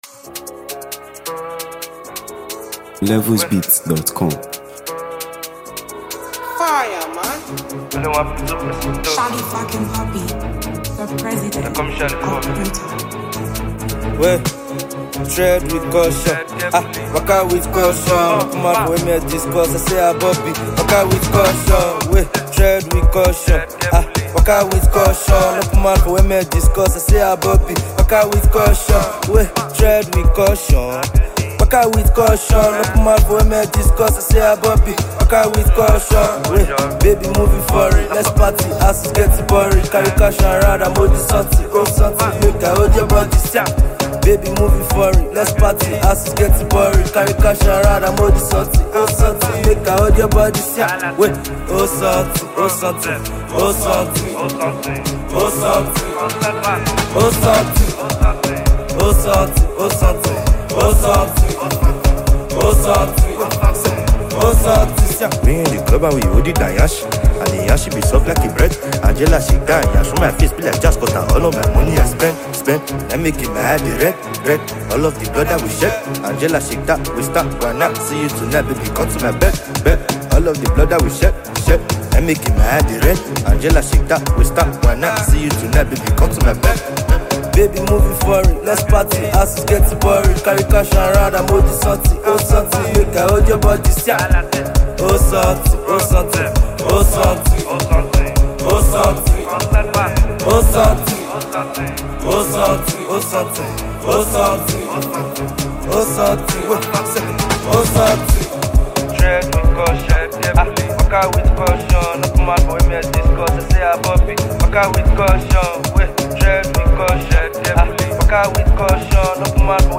Nigeria Music 2025 2:09
catchy hooks, and vibrant Afrobeat energy.
delivering infectious rhythms and unforgettable melodies